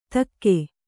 ♪ takke